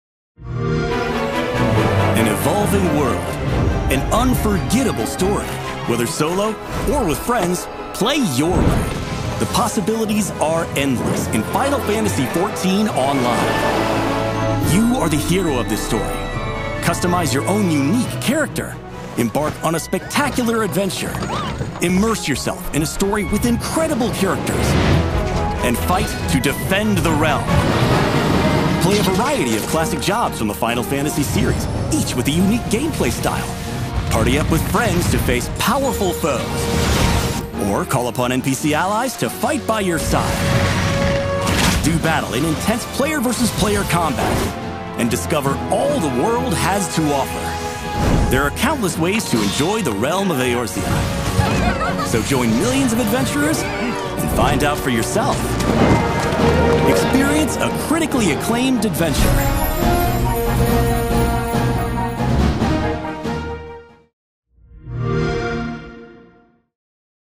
Middle-aged deep-voiced neutral/mid-western US accented voice talent with range on tone and
Final Fantasy 14 Online in 1 Minute - X-Box announce video